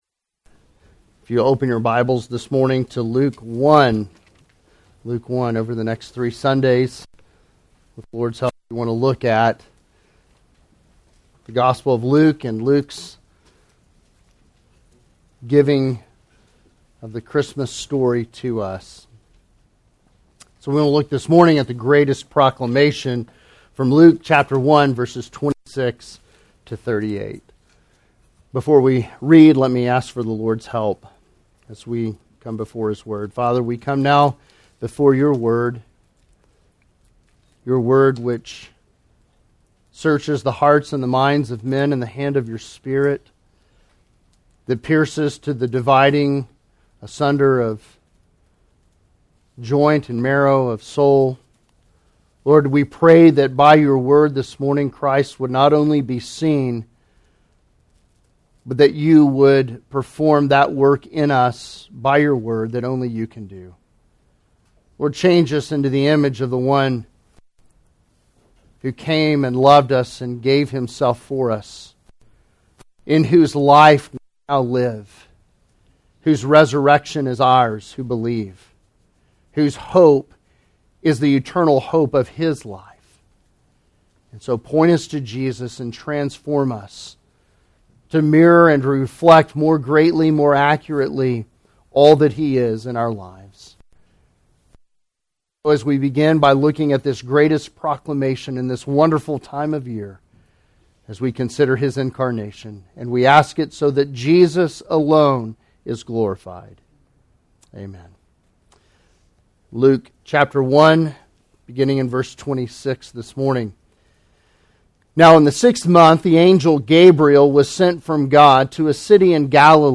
Sermons
Sermons from Colonial Bible Church: Midland, TX